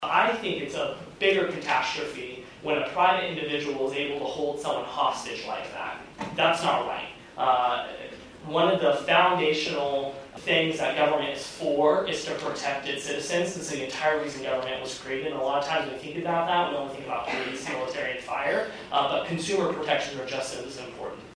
As the city works toward potentially bringing back a mandatory rental inspection ordinance which was repealed in 2011, commissioner Jerred McKee told students that a hurdle is convincing those who opposed the ordinance that it’s impeding a person’s privacy or more government intrusion.